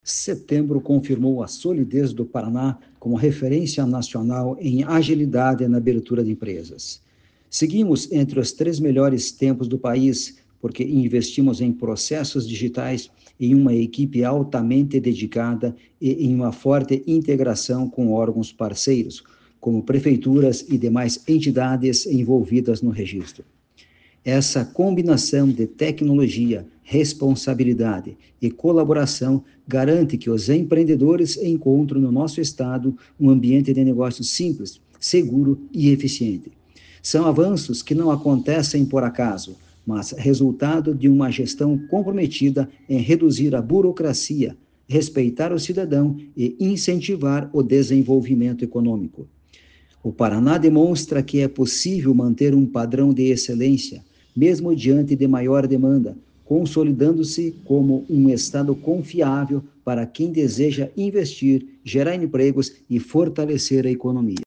Sonora do presidente da Jucepar, Marcos Rigoni, sobre o tempo de abertura de empresas no Paraná